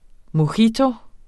Udtale [ moˈçiːto ]